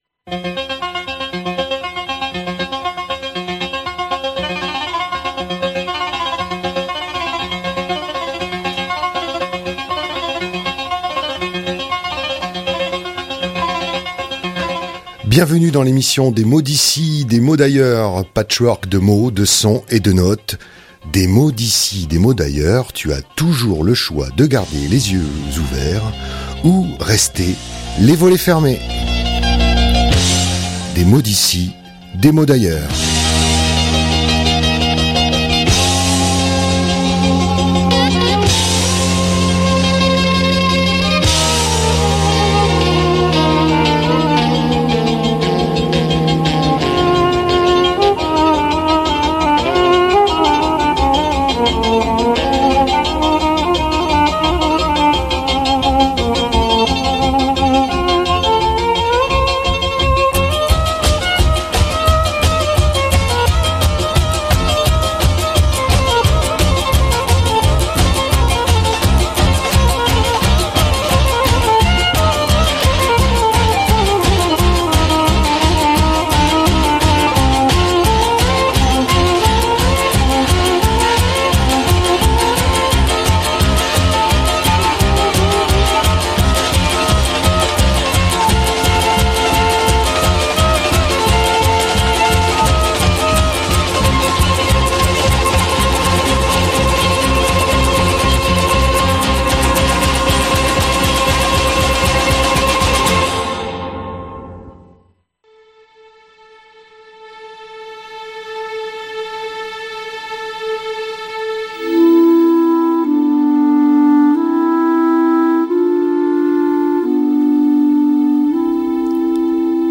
Mots D'ici Mots D'ailleurs Du 2019-10-03.mp3 (57.32 Mo) L'émission " Des mots d'ici, des mots d'ailleurs " est un patchwork de sons, de mots, de notes et de sons.